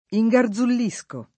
vai all'elenco alfabetico delle voci ingrandisci il carattere 100% rimpicciolisci il carattere stampa invia tramite posta elettronica codividi su Facebook ingarzullire v.; ingarzullisco [ i jg ar z ull &S ko ], ‑sci